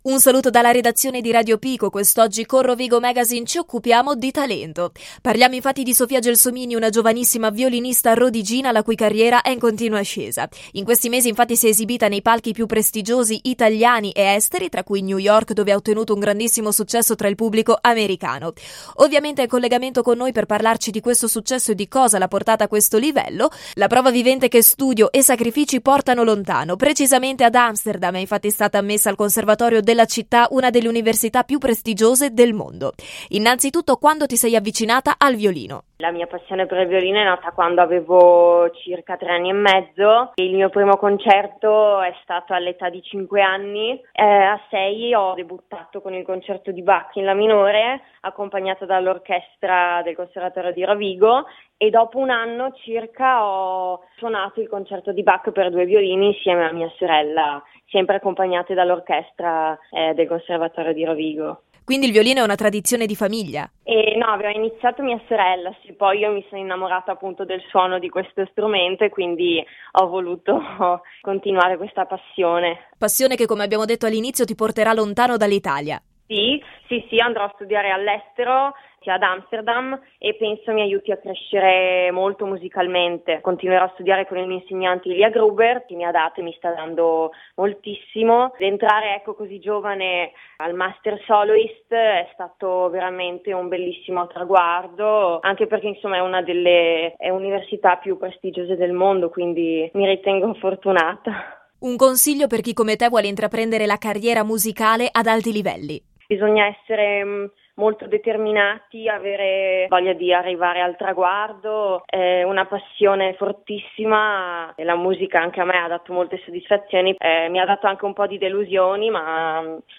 Ecco l’intervista: